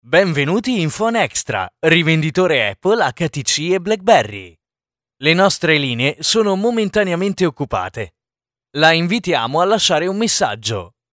Italian voice talent for all tipes of productions: voice overs, documentaries, narrations, commercials
Sprechprobe: Sonstiges (Muttersprache):
Native italian young male voice